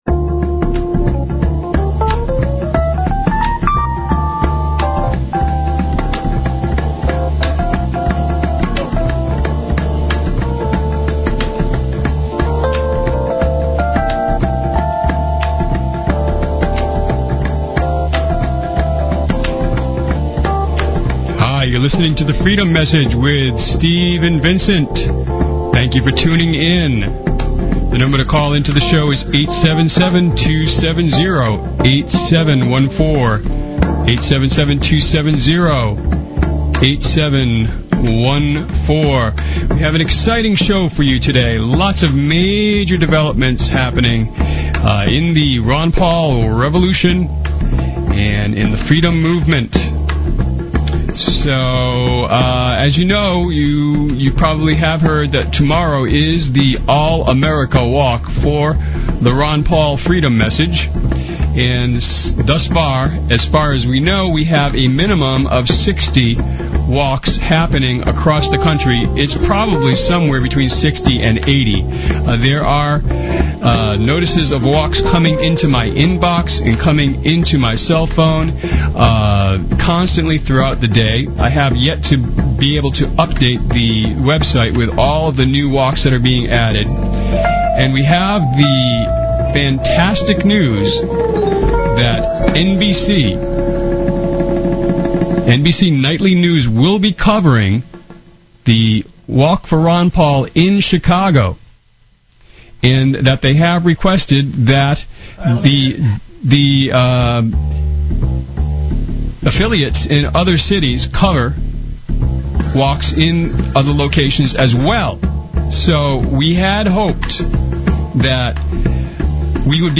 Talk Show Episode, Audio Podcast, The_Freedom_Message and Courtesy of BBS Radio on , show guests , about , categorized as
It's a live internet radio call in show for and about the Ron Paul Revolution. It serves as a media outlet for campaign announcements and news, a vehicle for the organization of Ron Paul support, a medium for the dissemination of ideas and tactics and a tool for the mobilization of large numbers of supporters in the movement to elect Ron Paul as our next President.